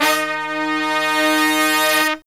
LONG HIT08-R.wav